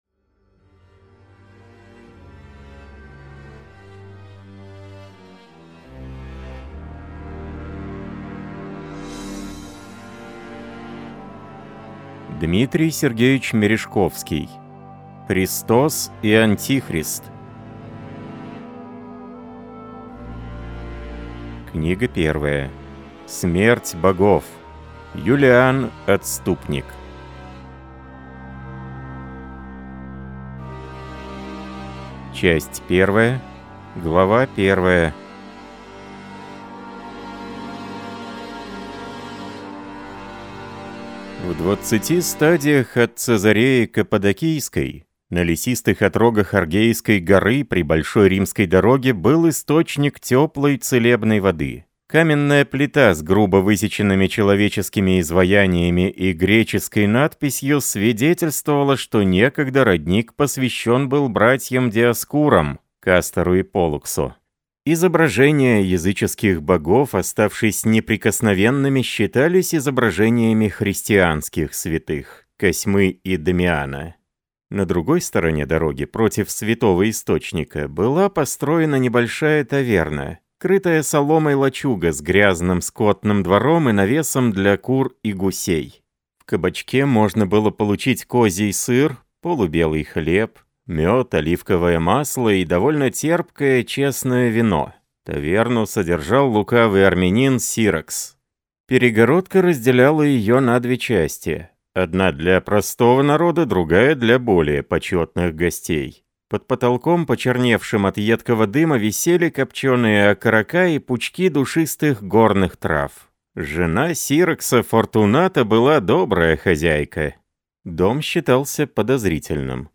Аудиокнига Христос и Антихрист. Книга 1. Смерть богов. Юлиан Отступник | Библиотека аудиокниг